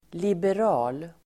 Uttal: [liber'a:l]